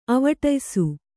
♪ avataysu